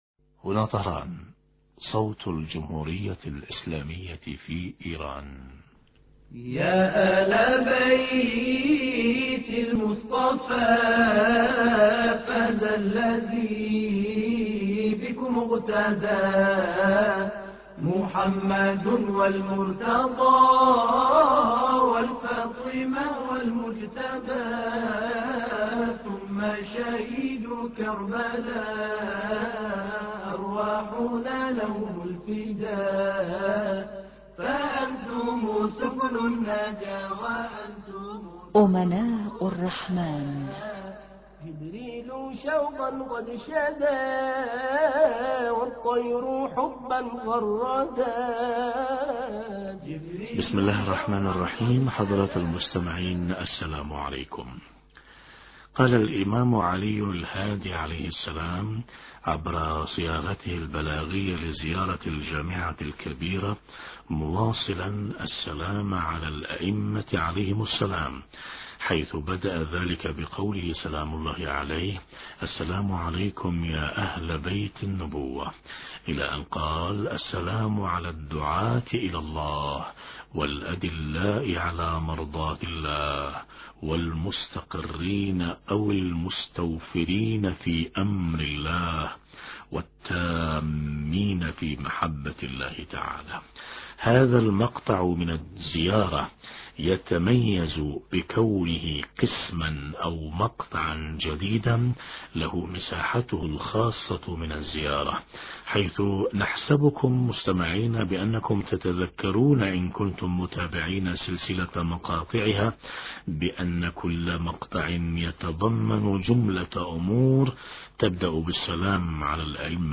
امناء الرحمن بهذا الاتصال الهاتفي